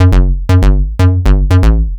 TSNRG2 Bassline 009.wav